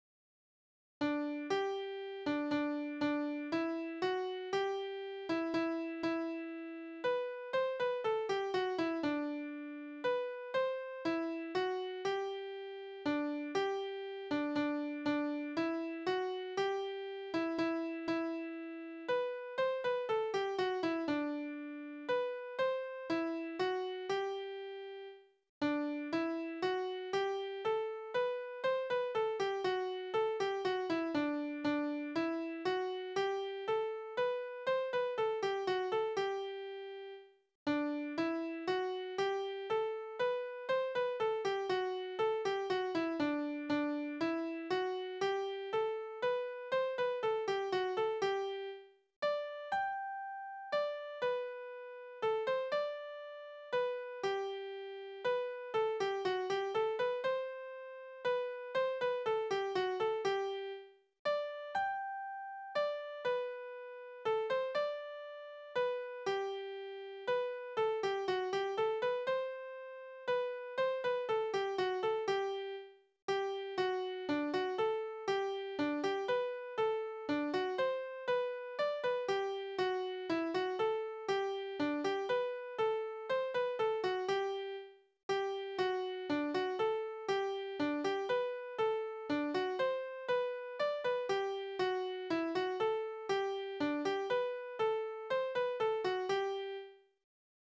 D 446 » Viertour (mit Walzer) Tanzverse: Tour Tonart: G-Dur Taktart: 3/4 Tonumfang: Undezime Besetzung: instrumental Externe Links: Sprache: hochdeutsch eingesendet aus Cretlow (um 1800) Fragen, Hinweise, Kritik?